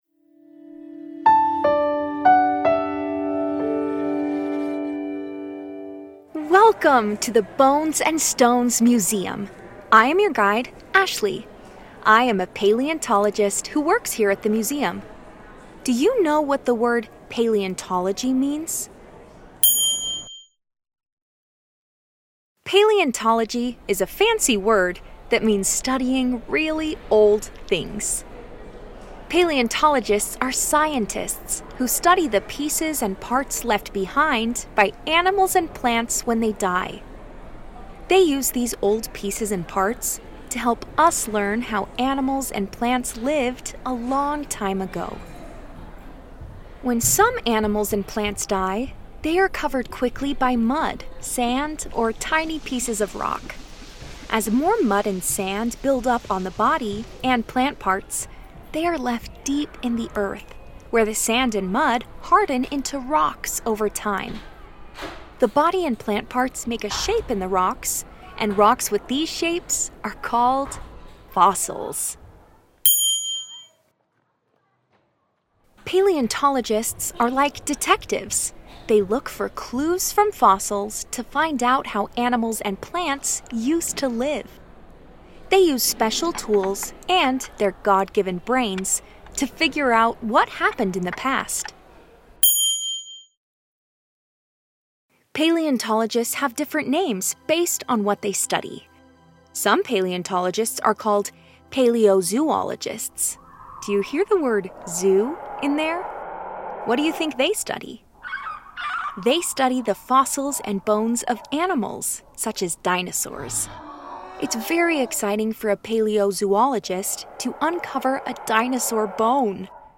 Bones-and-Stones-Lesson-1-What-Is-a-Paleontologist.mp3